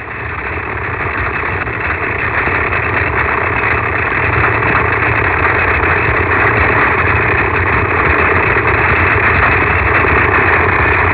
These shrimp live in colonies in such large numbers that there is continuous snapping, providing a permanent crackling background noise.
The frequency spectrum of a snap is extremely broad, ranging from tens of hertz to beyond 200 kHz. The snapping shrimp produces the impulsive click by an extremely rapid closure of its so-called snapper claw.
plenary_snapping.au